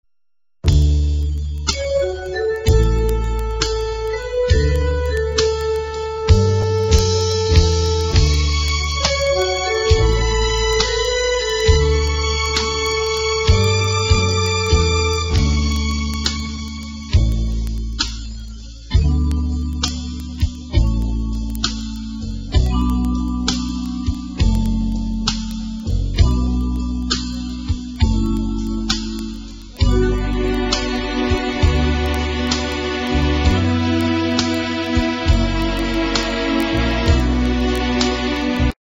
NOTE: Background Tracks 7 Thru 12